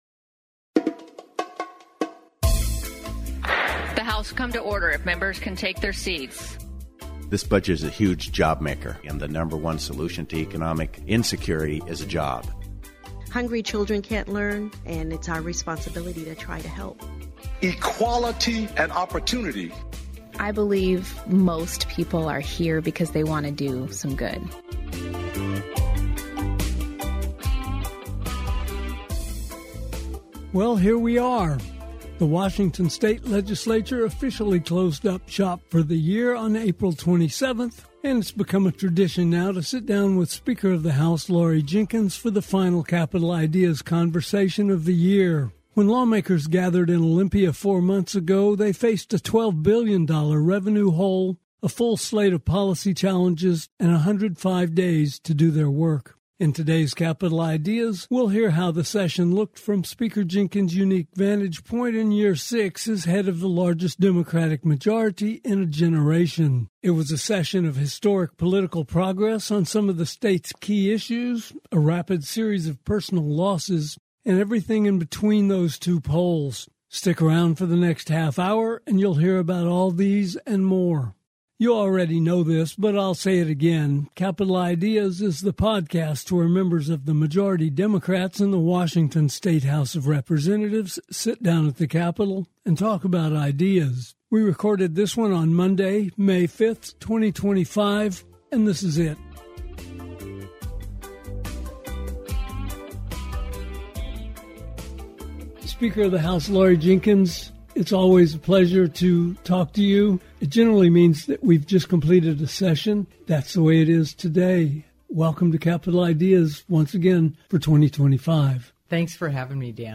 We're grateful to have Speaker Laurie Jinkins as today's guest on Capitol Ideas, to share her perspective on the just-ended 2025 session of the Washington state Legislature. Her sixth year leading the House of Representatives was by far the most difficult she's encountered, and today she walks us through what turned out to be a session marked by landmark victories and sometimes-painful realities.